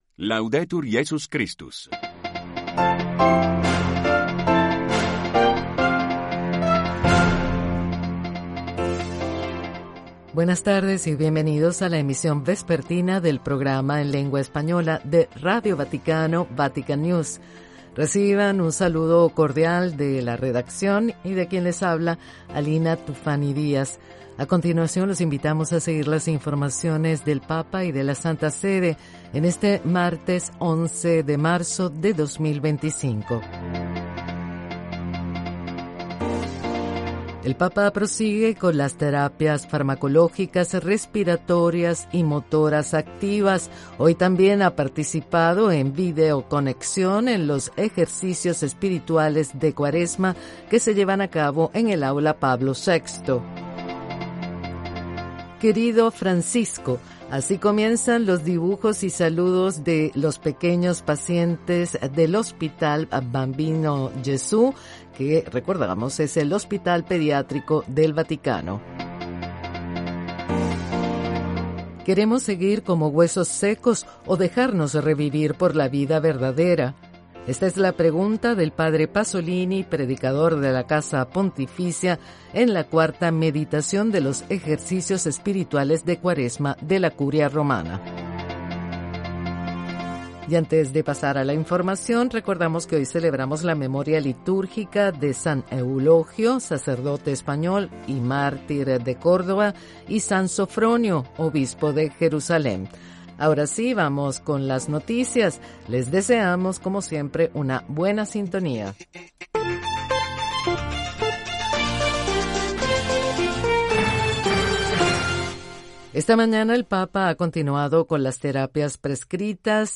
Informativos diarios en español